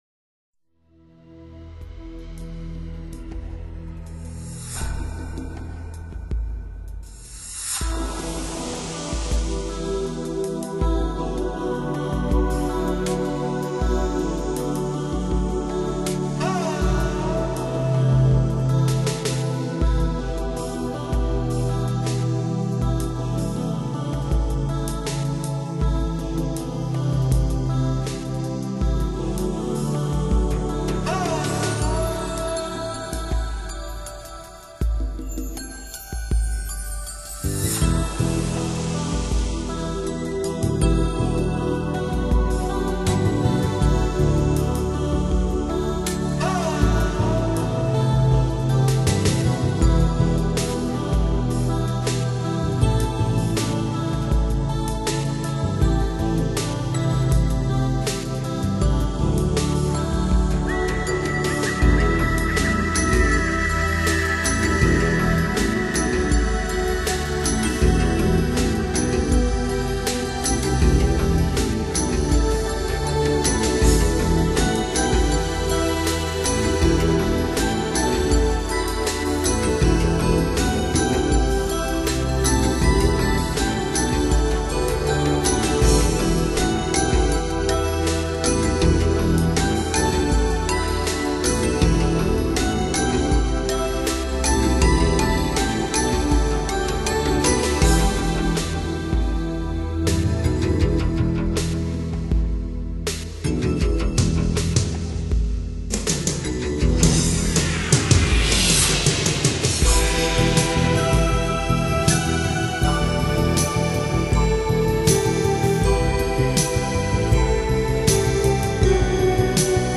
语种：纯音乐
电子器乐？梦幻般的效果，干净利落的处理，绵绵不绝的起伏！